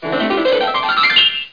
00685_Sound_flyout.mp3